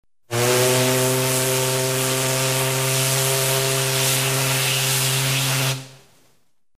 Звуки корабля, теплохода